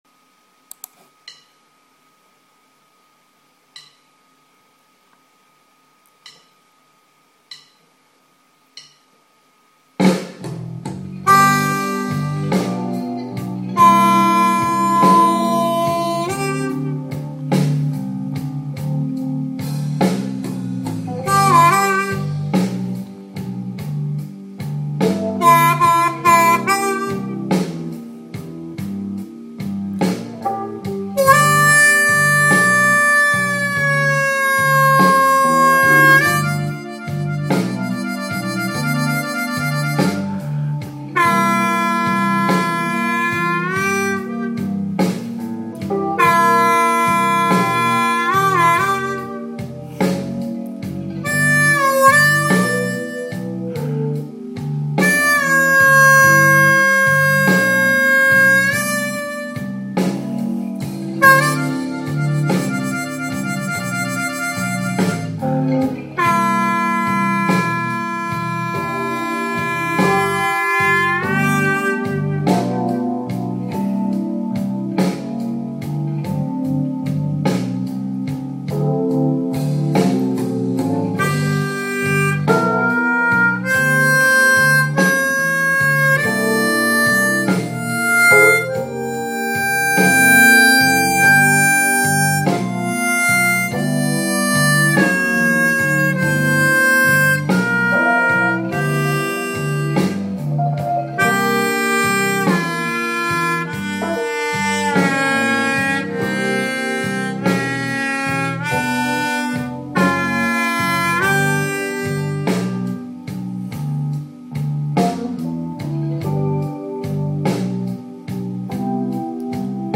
Приводим аудио пример, записанный под блюзовый минус.
Двойка и бенд на двойке
Пример того как можно отрабатывать блюзовую гамму, играя под минус.
Пример импровизации с использованием исключительно нот блюзовой гаммы и вышеописанных паттернов.
Simple-Blues-in-G.mp3